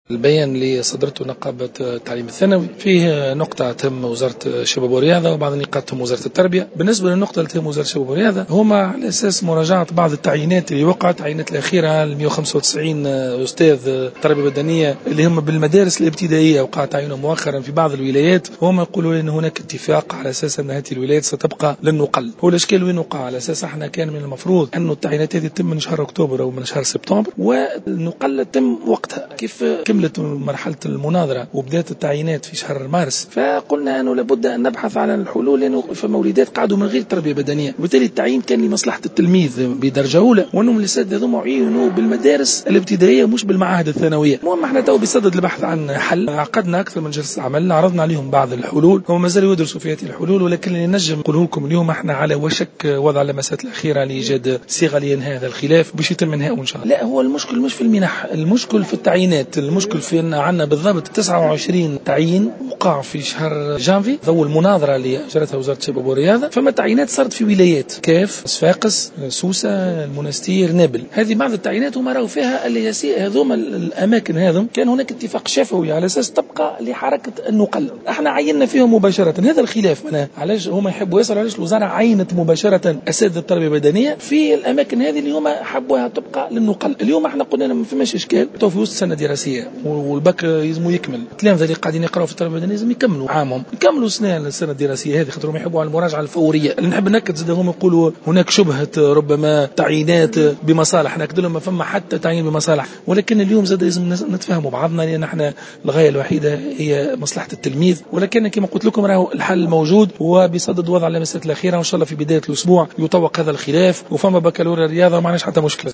وأضاف بن ضياء في تصريح اليوم لمراسلة "الجوهرة أف أم" على هامش ندوة وطنية لمتفقدي التربية البدنية والرياضية أنه تم عقد جلسات حوار مع الطرف النقابي، مشيرا إلى أنه من المنتظر أن يتم بداية الأسبوع القادم التوصل إلى صيغة لإنهاء الخلاف.